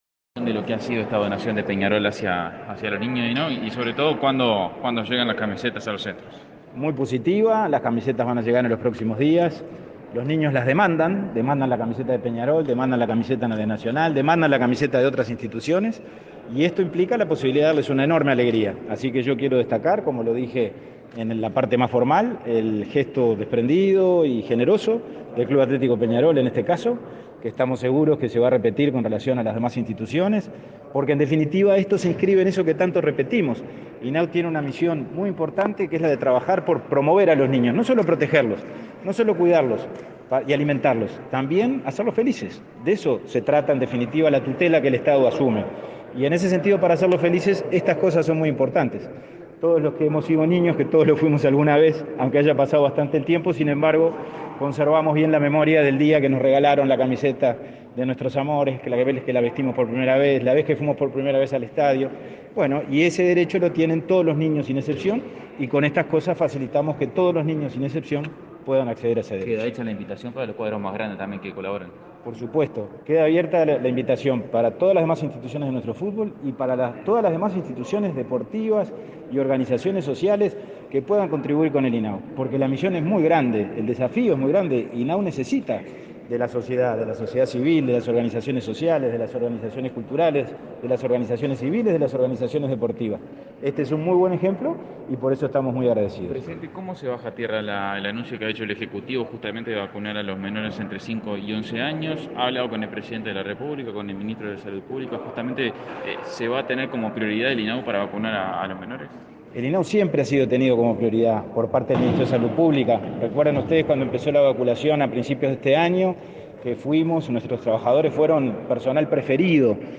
Declaraciones a la prensa del presidente del INAU, Pablo Abdala